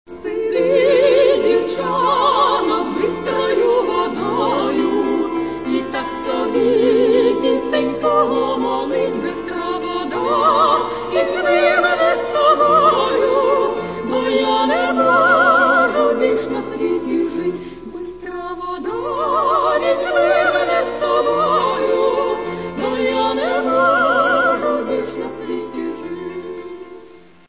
music: folk song